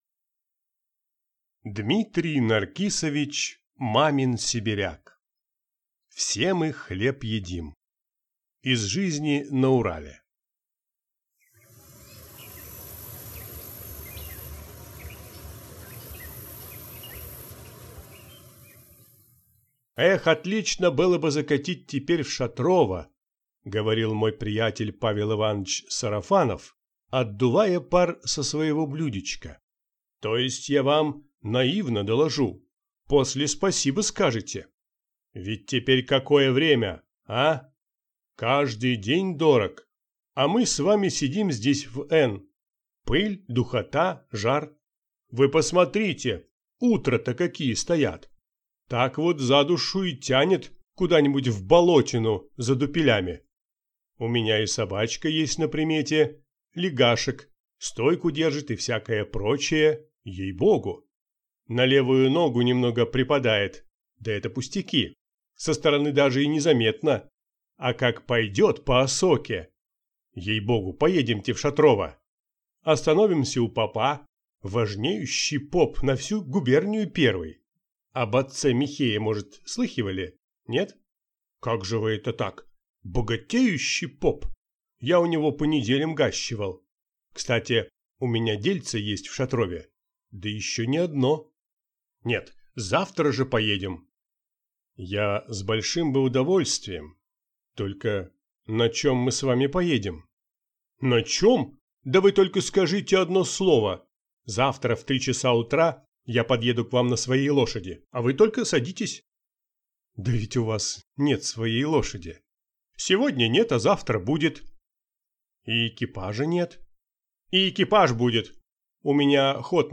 Аудиокнига «Все мы хлеб едим…» Из жизни на Урале | Библиотека аудиокниг